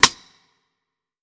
Air Rim.wav